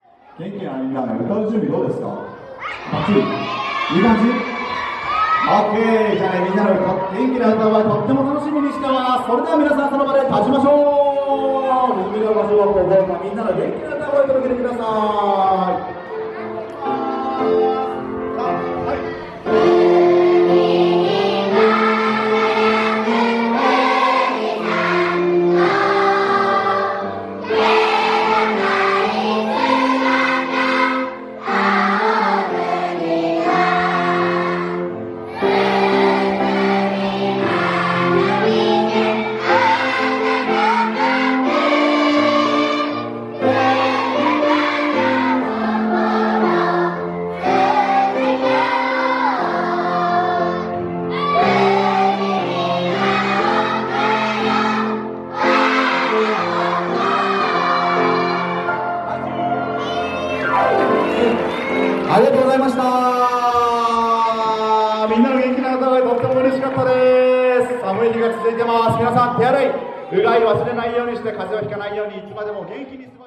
プロの音楽グループ「Everlyエバリー」のみなさんに「訪問コンサート」を開催していただきました。
様々なリズムに乗って、体を動かした後は、元気いっぱいの
校歌斉唱で締めくくりました。